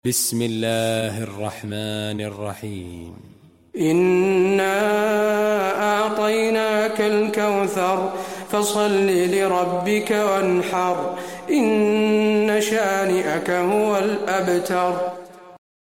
المكان: المسجد النبوي الكوثر